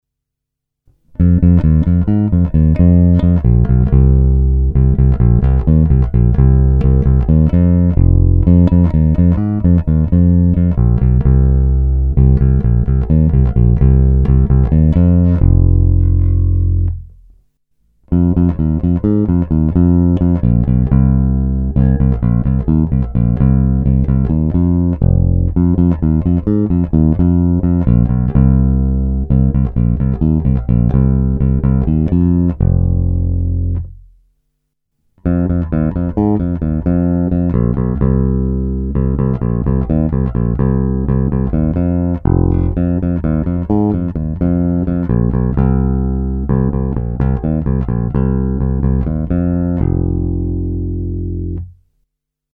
Není-li uvedeno jinak, následující nahrávky byly provedeny rovnou do zvukové karty a dále kromě normalizace ponechány v původním stavu.